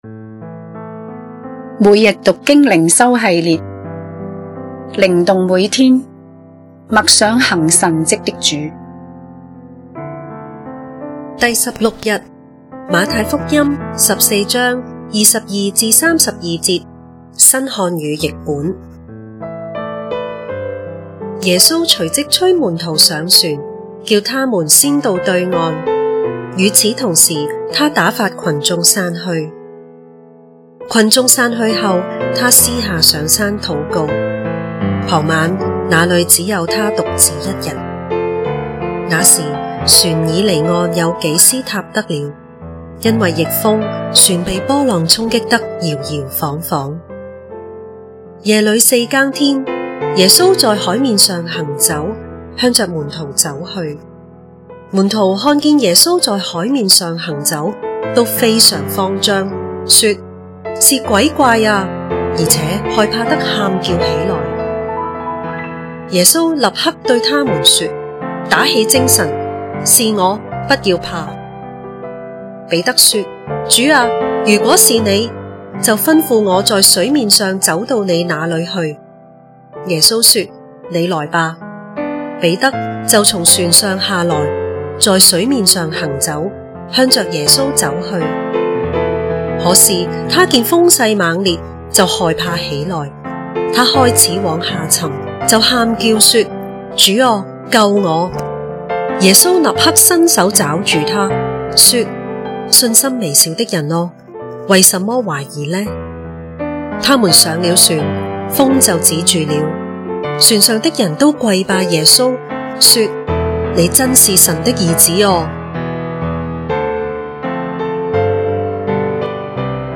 閱讀經文：太14:22-32 新漢語 22 耶穌隨即催門徒上船，叫他們先到對岸；與此同時，他打發羣眾散去。